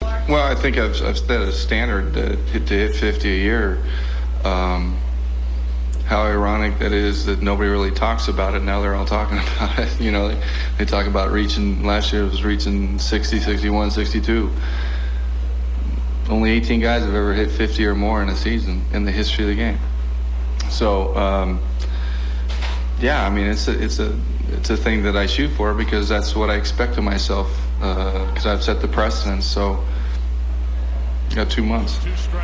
RA Audio: Press Conference on "50 HRs a Season" - 05 AUG 1999